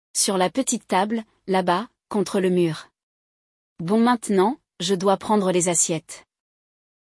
No episódio de hoje, você vai acompanhar a conversa entre duas amigas em que uma delas conta sobre o que ganhou da irmã e da mãe de natal.